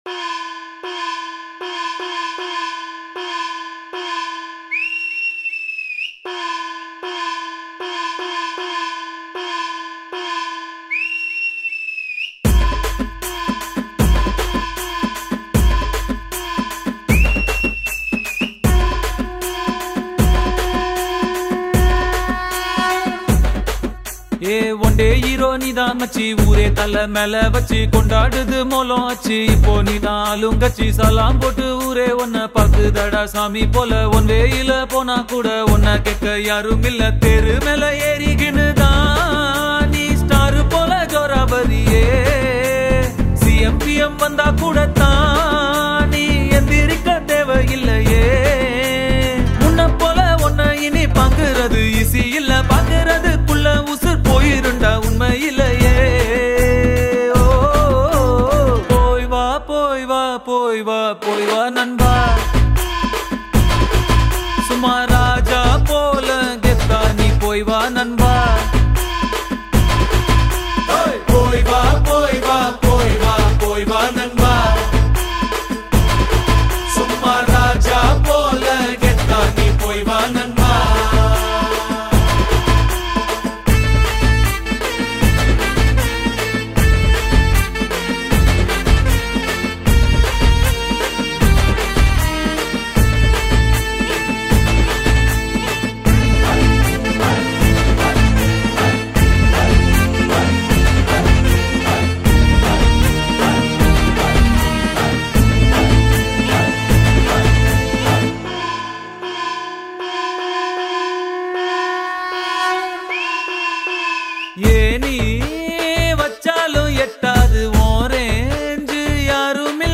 Tamil Gana